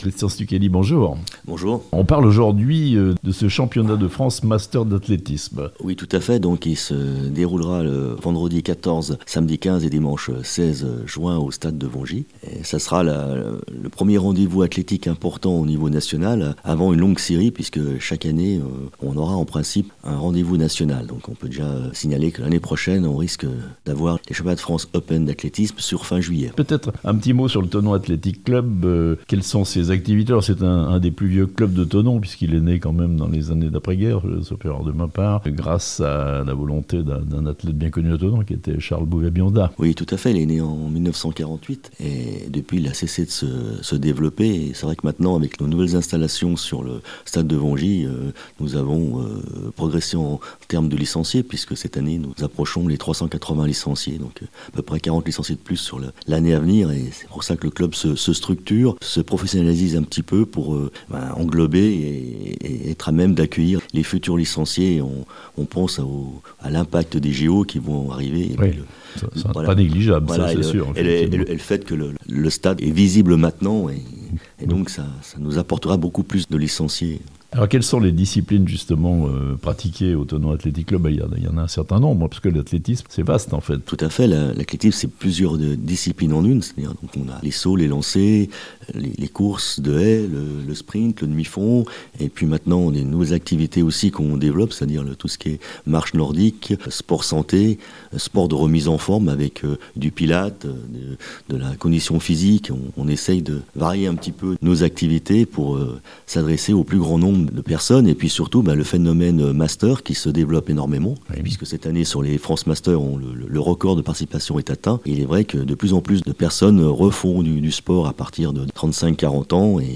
Les championnats de France Masters d'athlétisme se déroulent à Thonon du 15 au 16 juin (interview)